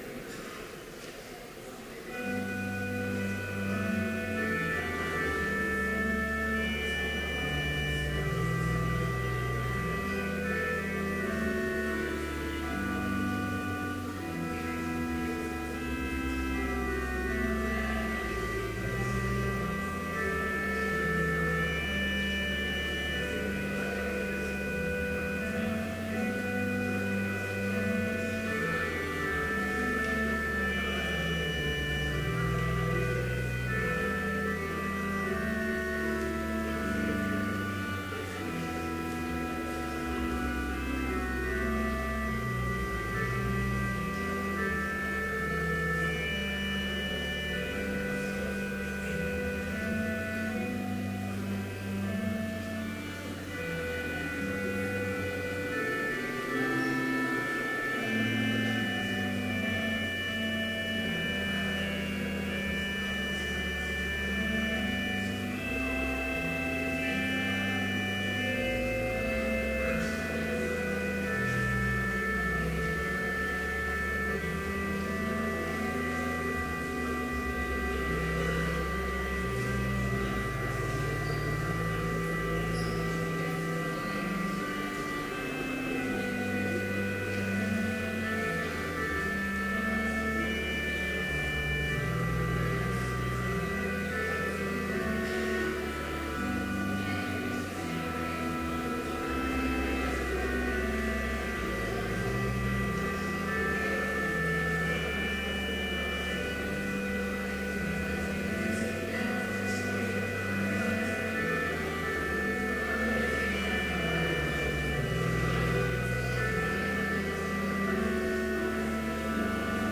Complete service audio for Chapel - September 15, 2014
Prelude